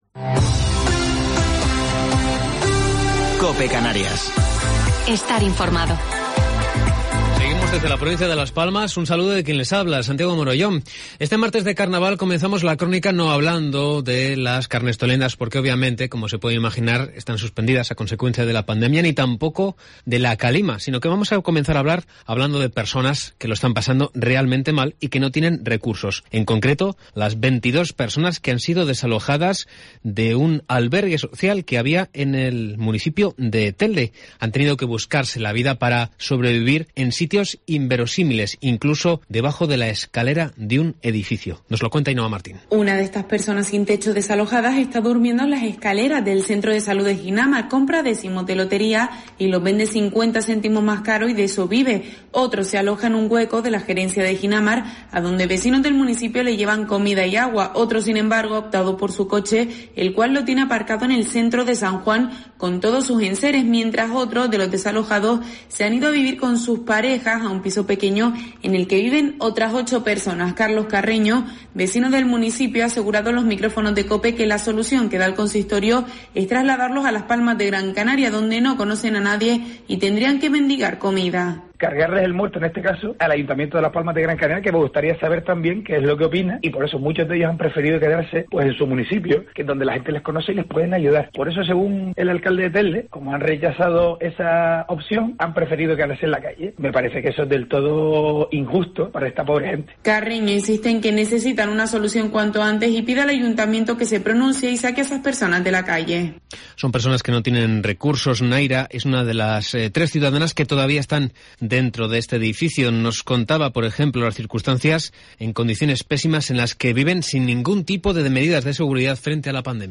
Informativo local 16 de Febrero del 2021